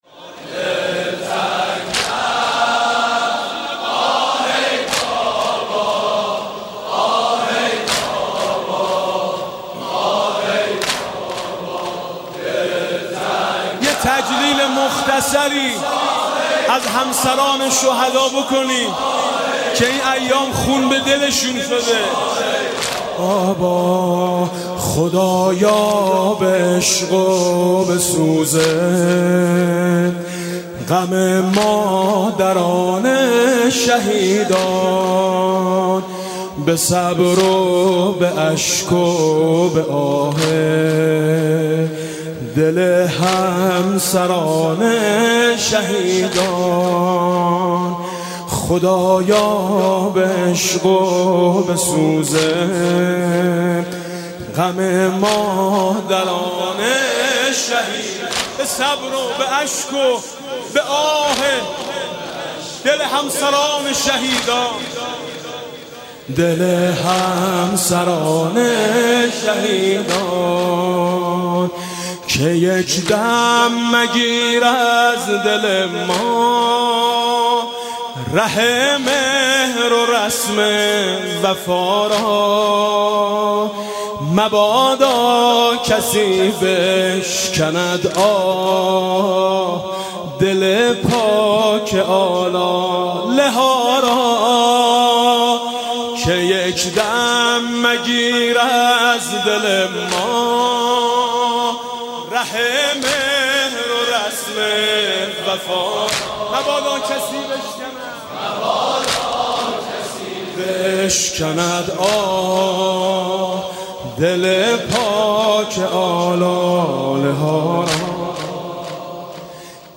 مداحی میثم مطیعی در تجلیل از مادران و همسران شهیدان